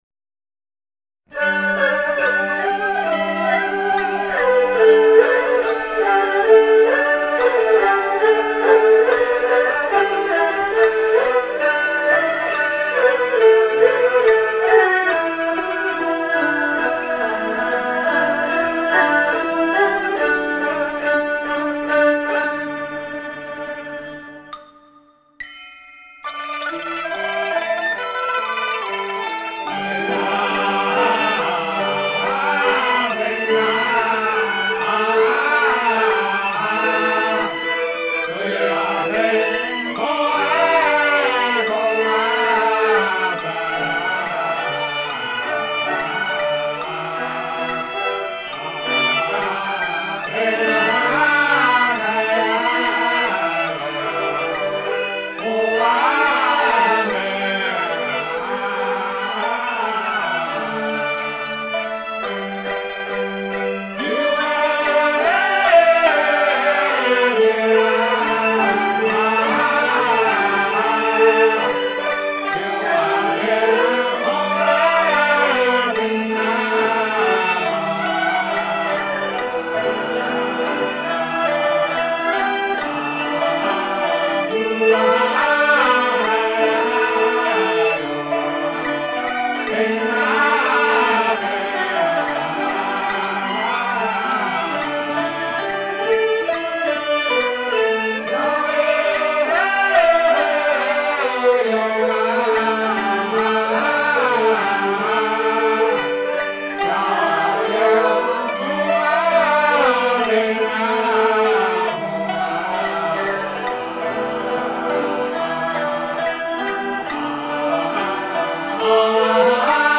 江西上饶道教音乐—灵宝正韵—水巽魔宫摄 - 道音文化
音乐古朴，飘逸，表达对神仙崇仰之情。
上饶的道教音乐具有明显的地域性，具有当地的风格色彩，道教音乐充满高雅阴柔，和谐宁静的听觉感受。